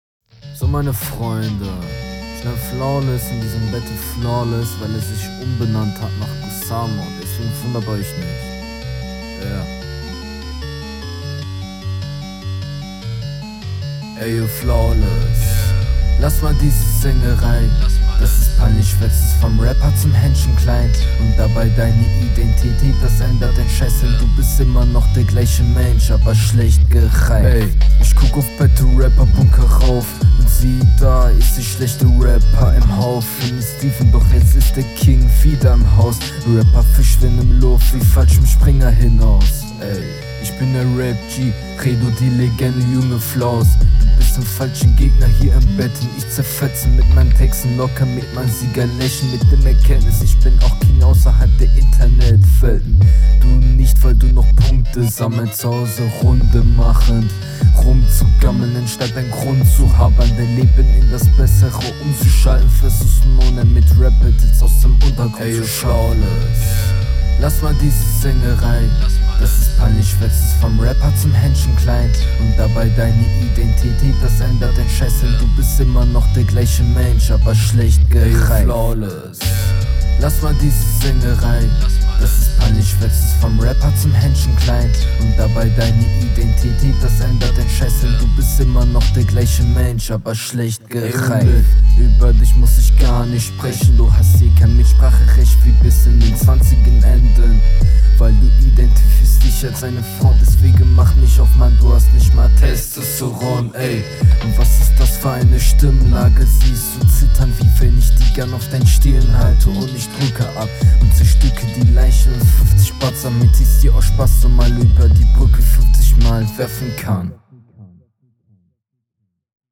ist nicht dein beat.